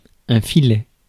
Ääntäminen
IPA: [fi.lɛ]